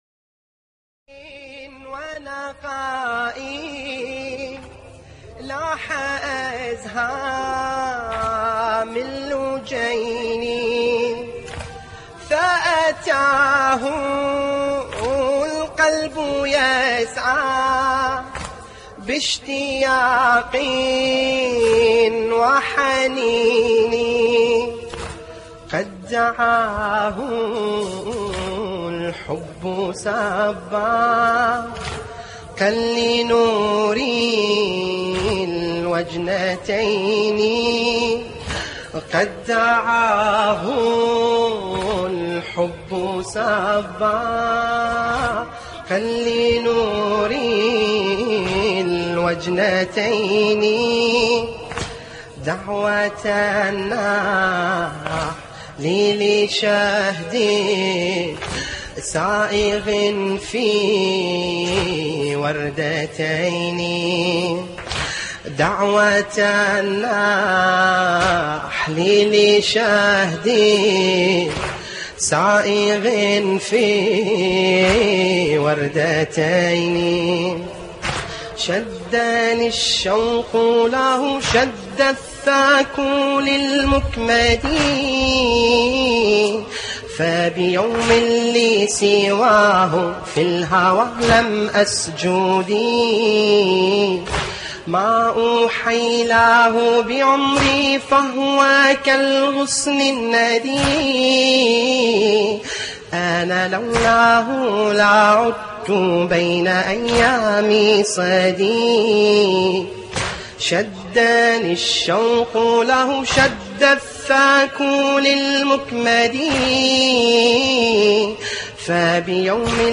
تحميل : لاح ازها من لجيني( قم إلينا أيا زين العباد 1) / مجموعة من الرواديد / اللطميات الحسينية / موقع يا حسين